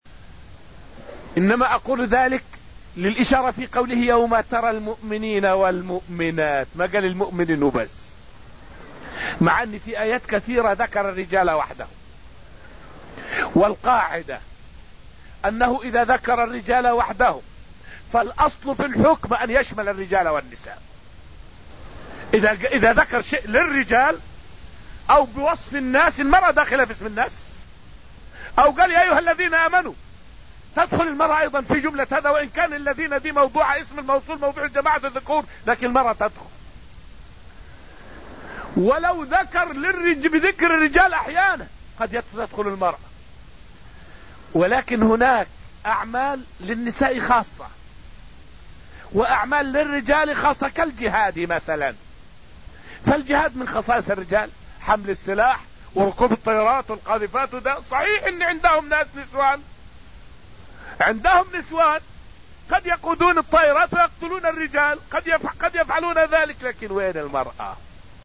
فائدة من الدرس الثالث عشر من دروس تفسير سورة الحديد والتي ألقيت في المسجد النبوي الشريف حول دلالة ذكر الرجال والنساء في قوله: {يَوْمَ تَرَى الْمُؤْمِنِينَ وَالْمُؤْمِنَاتِ}.